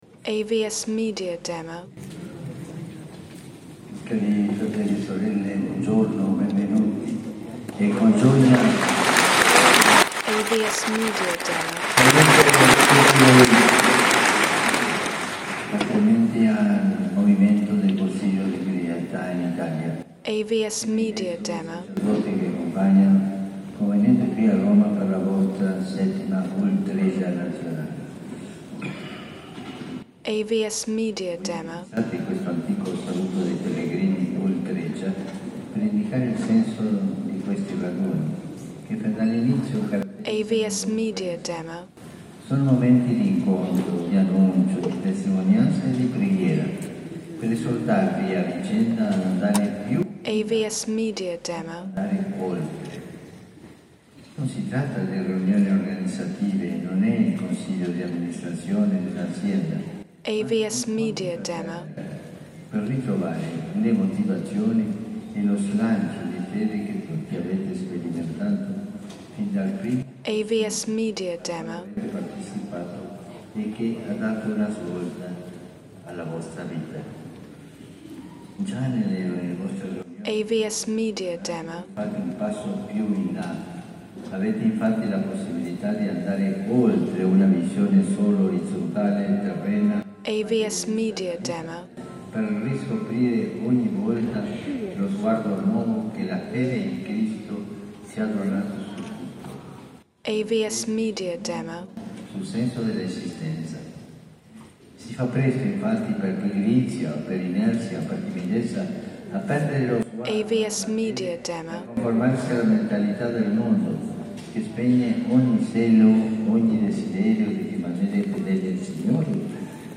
Incontro con Papa Francesco Discorso del Papa
Ascolta la voce del Papa